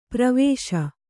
♪ pravēśa